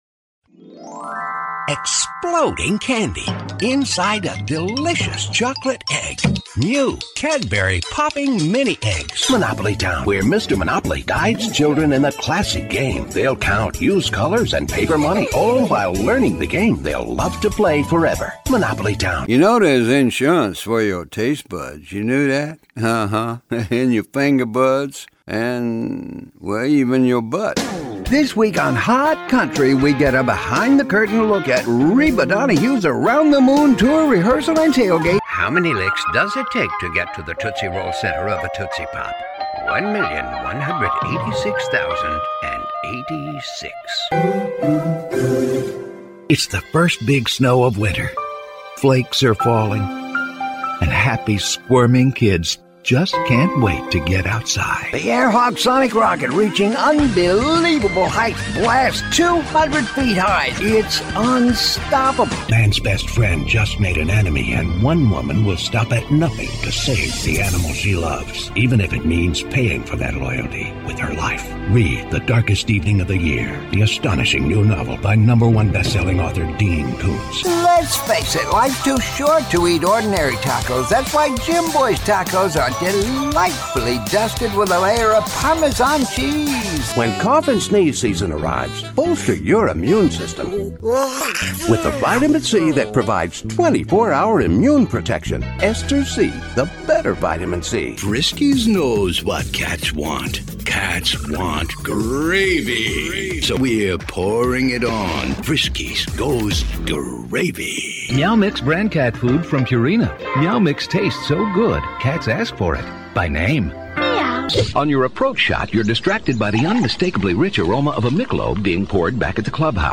Middle Aged
Quiet in-house audio booth. Classic 1981 Neumann U-87.